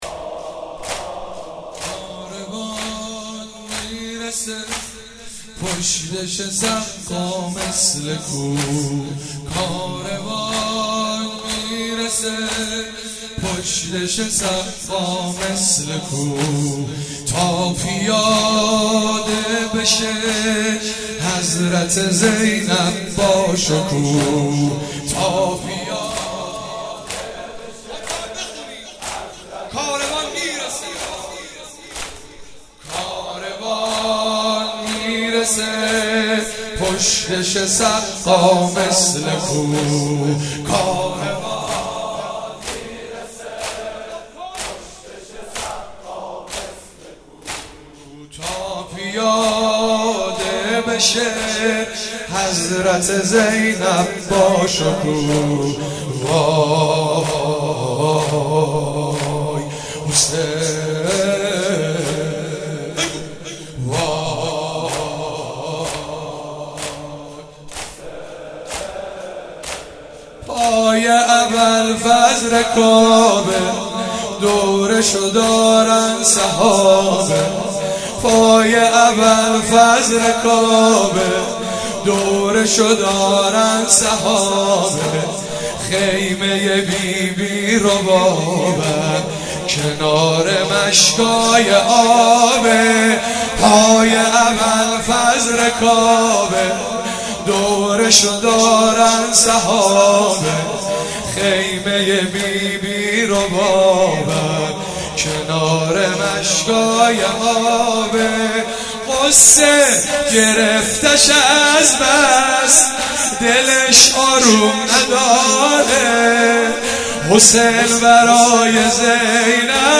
مراسم شب دوم محرم الحرام 96
چهاراه شهید شیرودی حسینیه حضرت زینب (سلام الله علیها)
سنگین- کاروان آمده در این وادی دشت بلا